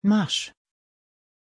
Aussprache von Mars
pronunciation-mars-sv.mp3